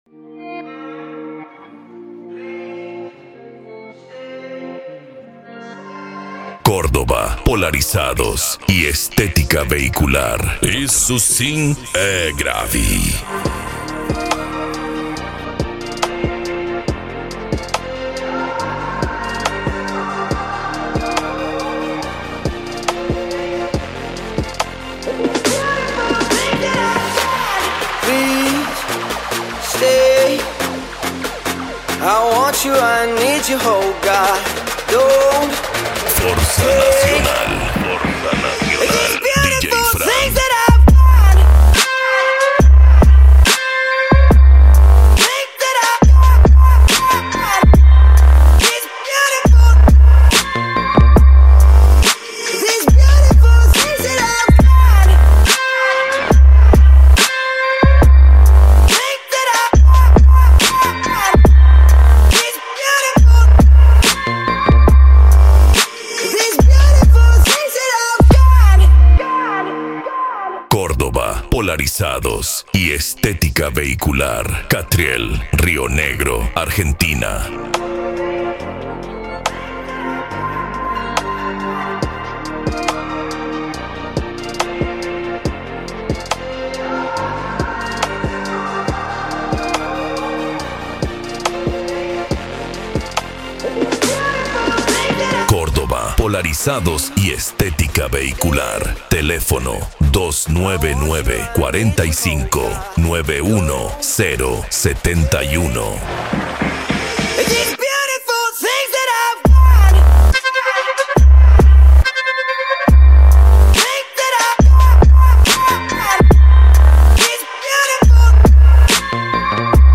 Bass
Electro House
Eletronica
Musica Electronica